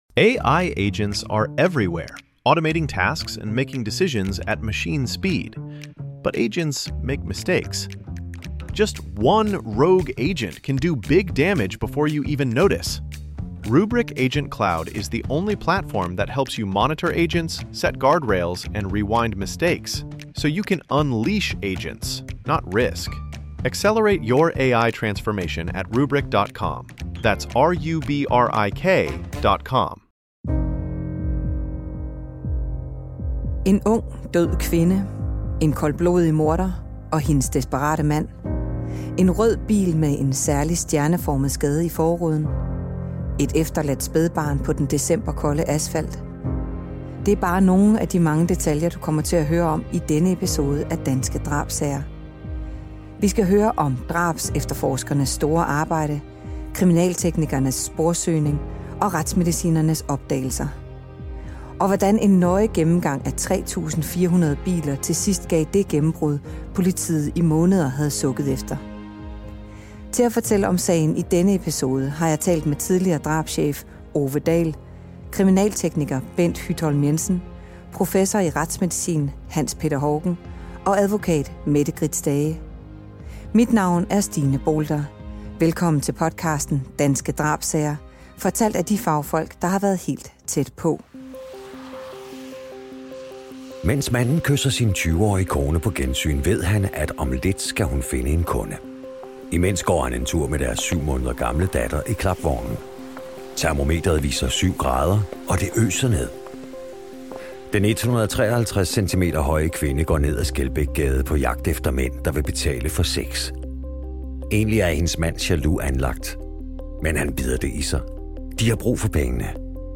Eksperterne fortæller om sagen, som tog flere måneder at opklaret, og hvordan en nøje gennemgang af 3400 biler over hele landet gav til sidst gav det gennembrud, politiet havde sukket efter.